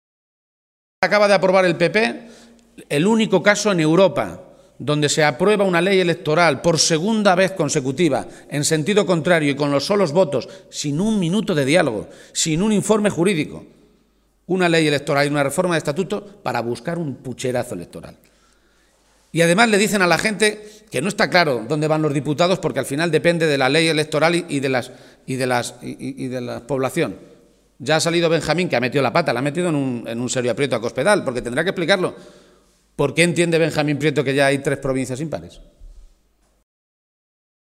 El líder de los socialistas castellano-manchegos hacía estas manifestaciones en la capital conquense, donde denunció además que “Cospedal se ceba particularmente con Cuenca” a través de su política de recortes en sanidad, educación y servicios sociales.